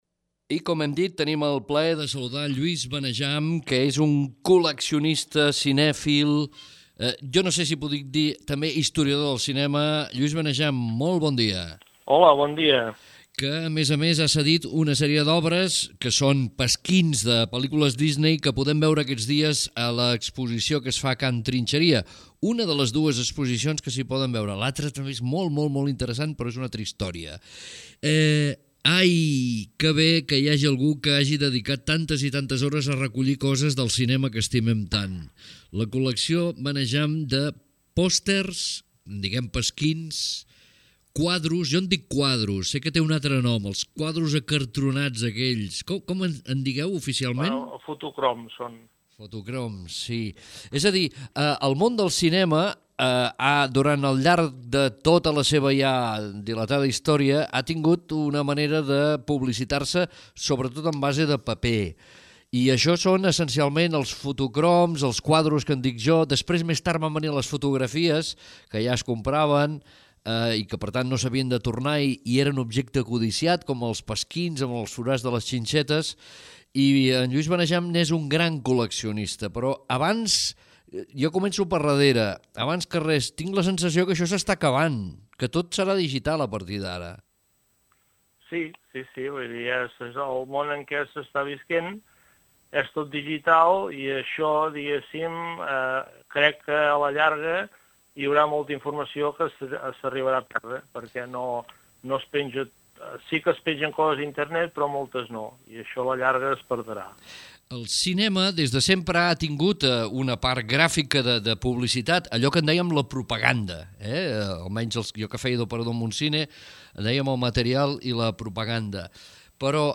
El día 7 de febrero de 2018 me llamaron para conversar sobre la exposición y el archivo de cine que estoy haciendo.